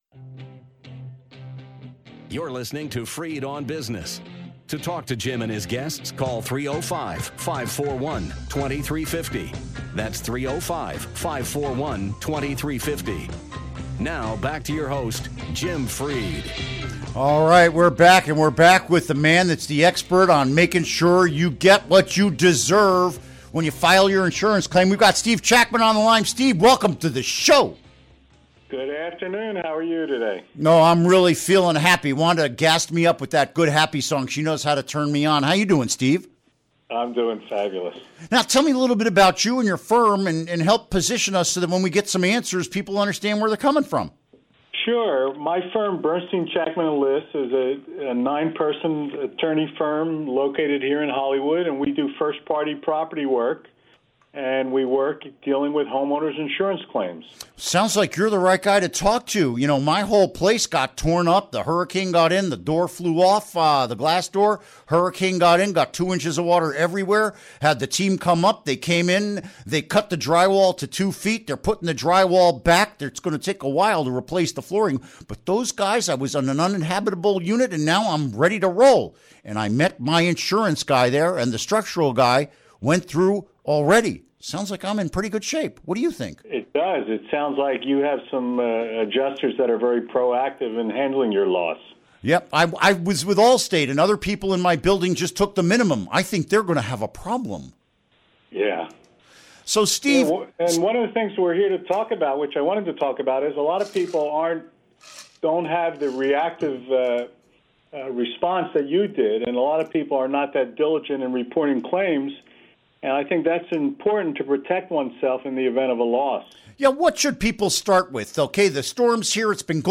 Interview Segment Download Now!